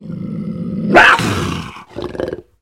puma-sound